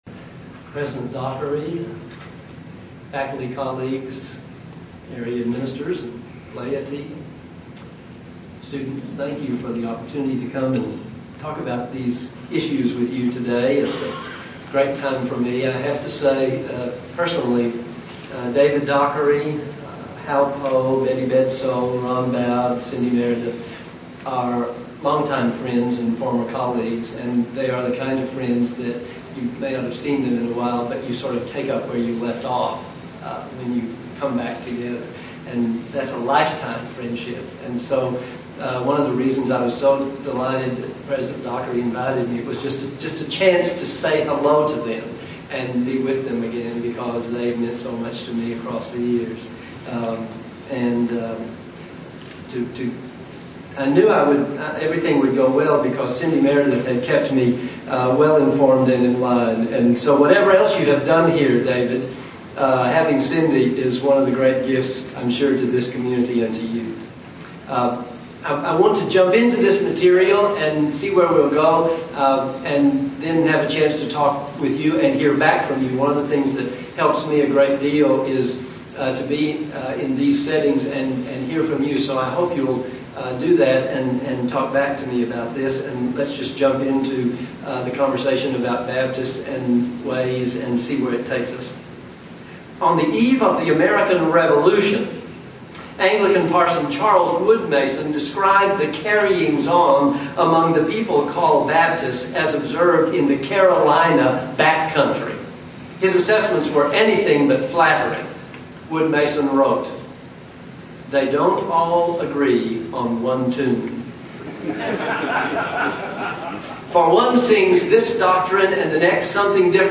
Baptists in America Conference: Baptist Way or Baptist Ways?